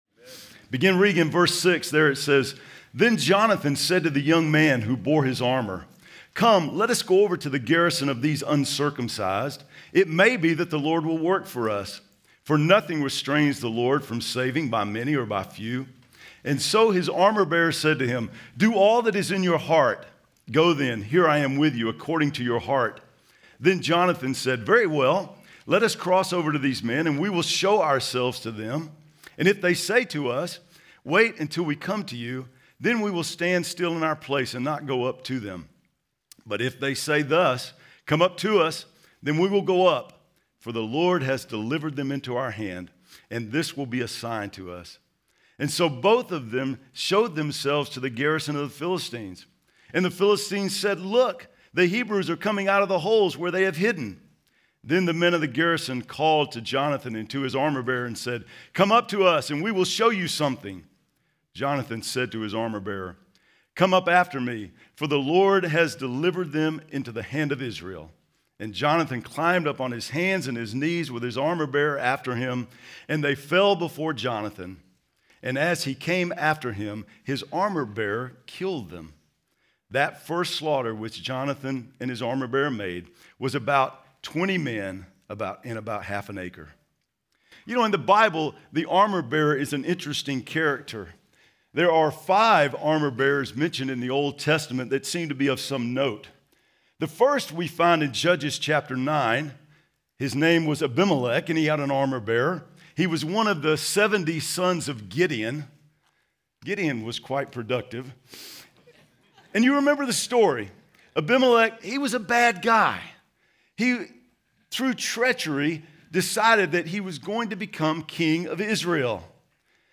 2025 DSPC Conference: Pastors & Leaders Date